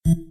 Play Electro Beep - SoundBoardGuy
Play, download and share Electro beep original sound button!!!!
electro-beep.mp3